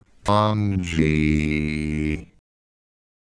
Worms speechbanks
Bungee.wav